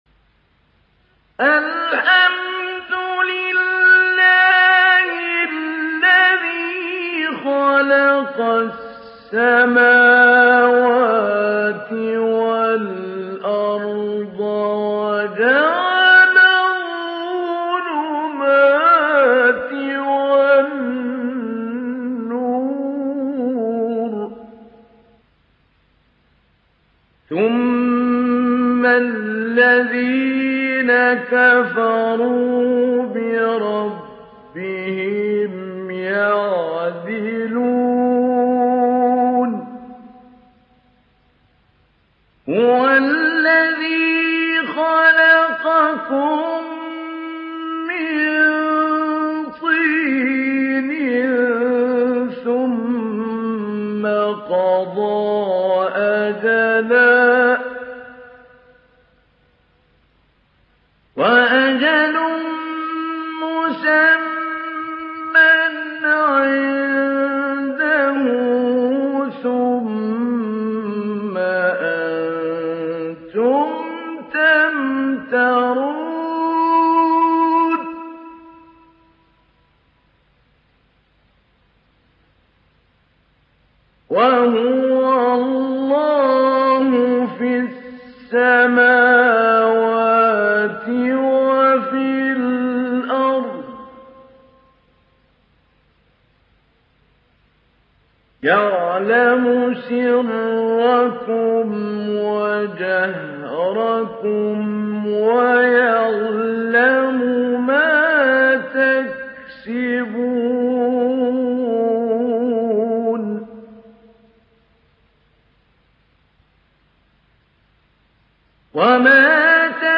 تحميل سورة الأنعام mp3 بصوت محمود علي البنا مجود برواية حفص عن عاصم, تحميل استماع القرآن الكريم على الجوال mp3 كاملا بروابط مباشرة وسريعة
تحميل سورة الأنعام محمود علي البنا مجود